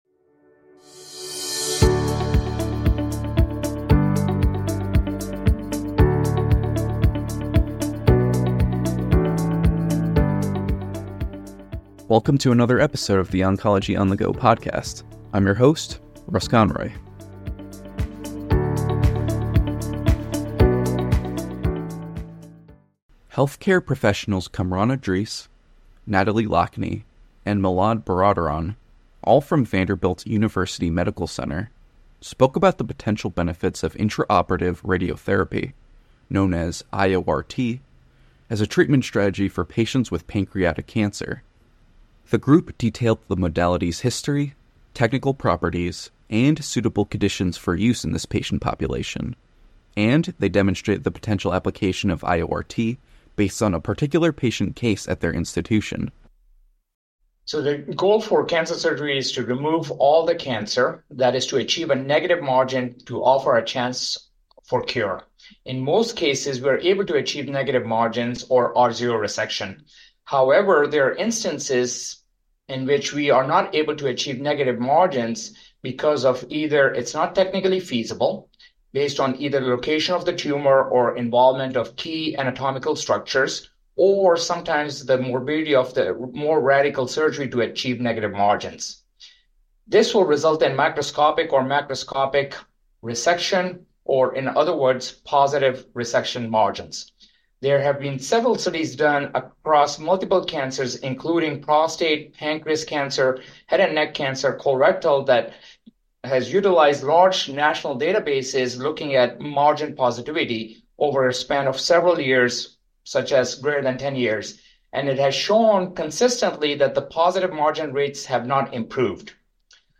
Experts from Vanderbilt Medical Center review mechanisms, supporting data, and patient cases associated with the use of IORT in pancreatic cancer.